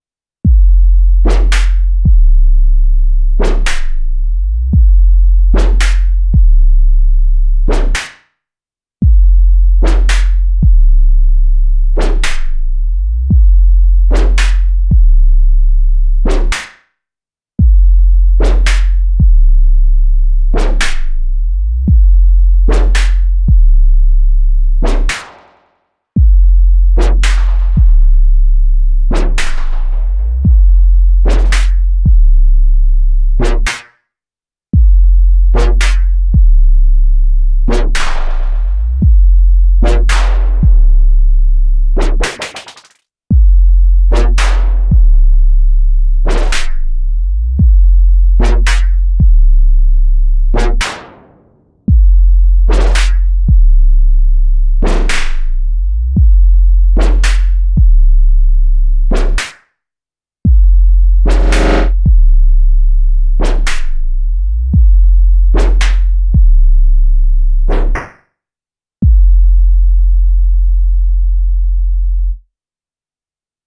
D04 was really cool. Super simple but some very Nice P-looking to get that swooshing whip sound.
Also plockning the depth of an lfo with an upward ramp on pitch to get that pitch going up on the kick…
Looking through the kicks as well it reaffirms that you need atleast two LFOs on pitch to get the kick pumping.